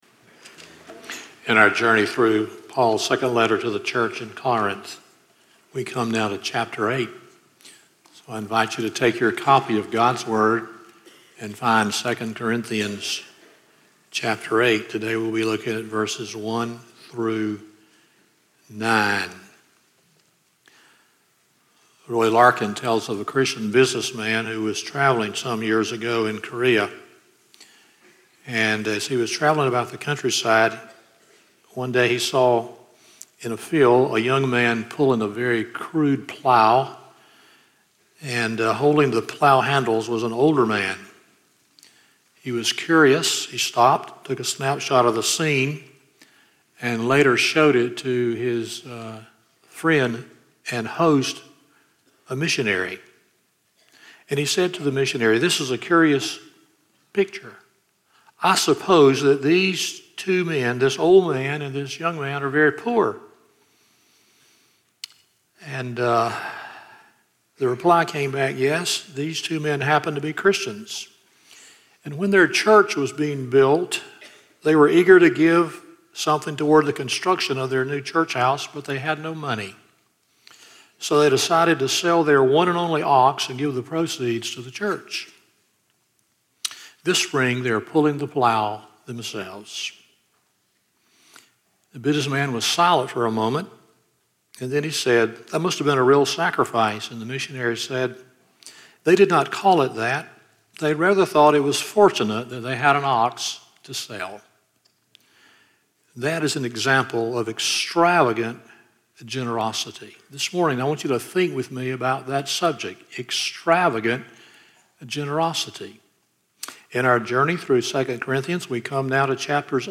2 Corinthians 8:1-9 Service Type: Sunday Morning 1.